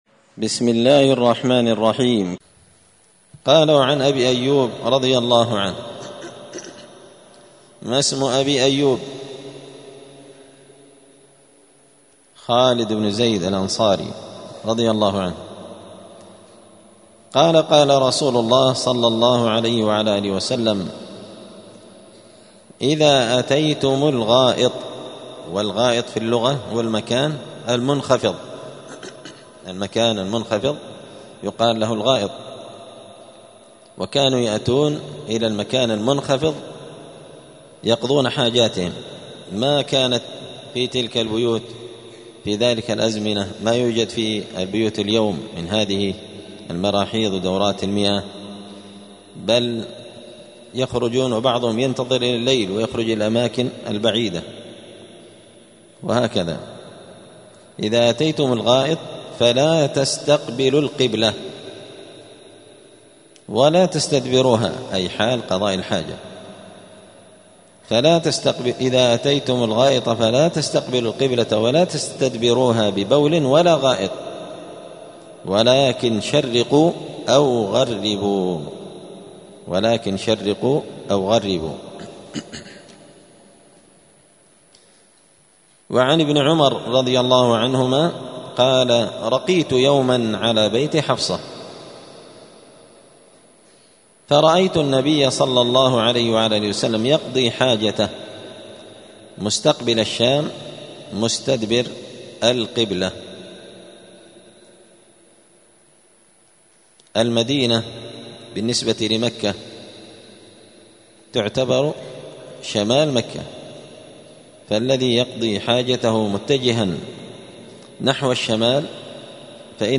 دار الحديث السلفية بمسجد الفرقان قشن المهرة اليمن
*الدرس الثالث والستون [63] {باب الاستطابة عدم استقبال القبلة عند قضاء الحاجة}*